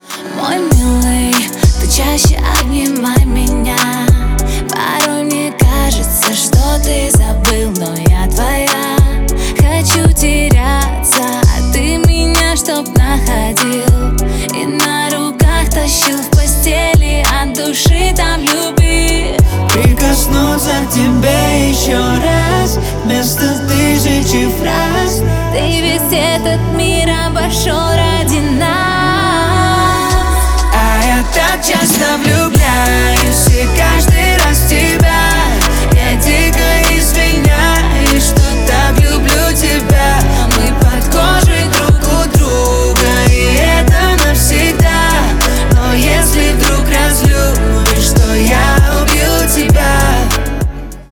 • Качество: 320, Stereo
поп
дуэт